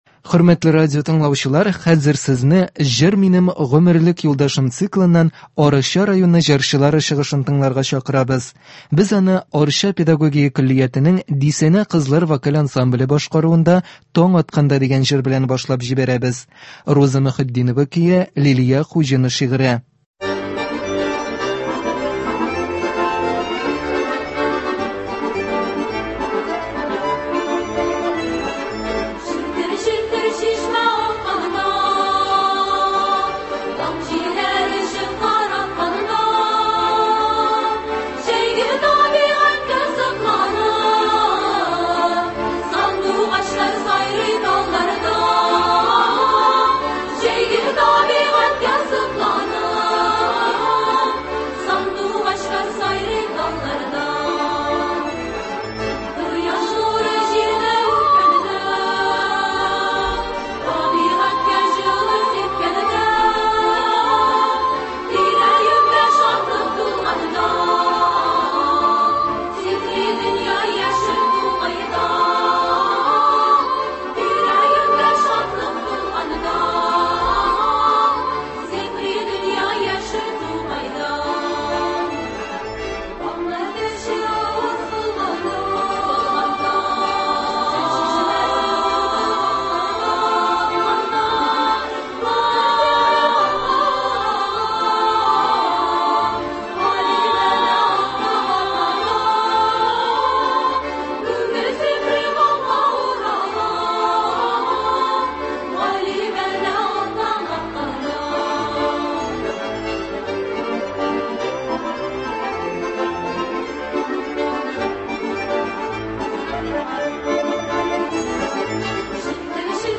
Концерт (30.05.22)